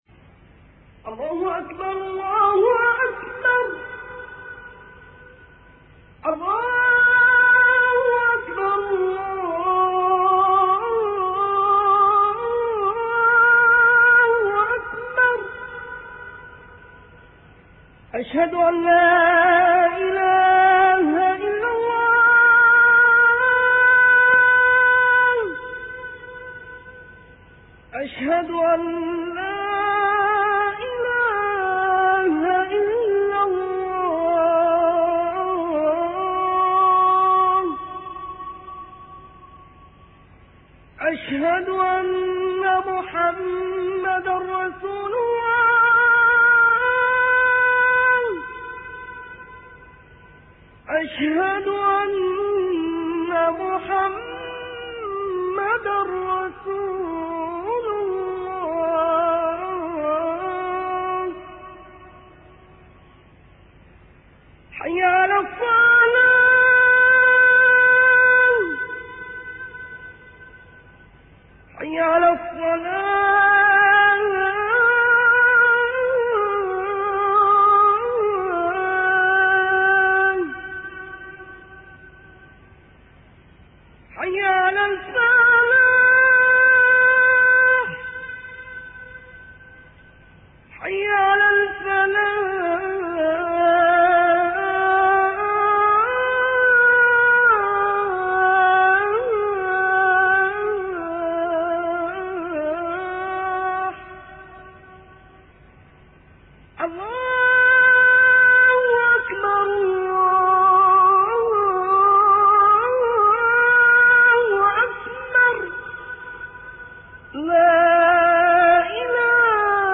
أناشيد ونغمات
عنوان المادة أذان-3